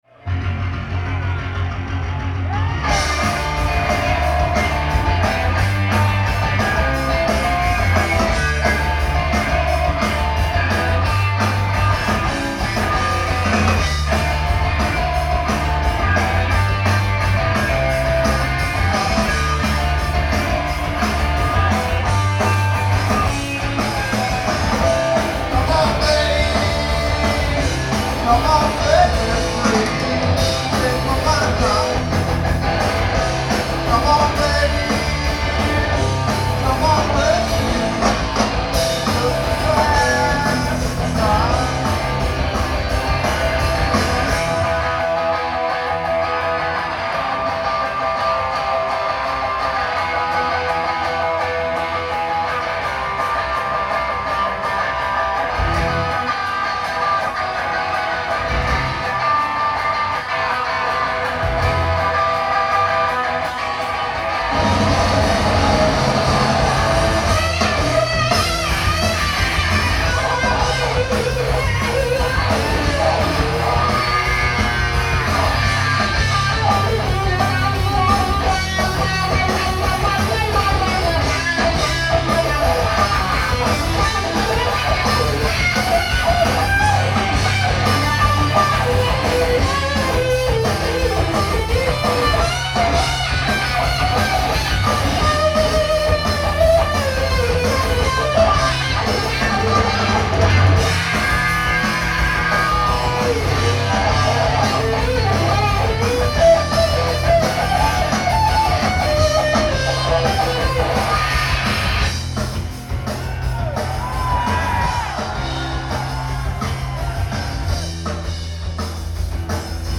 live at Avalon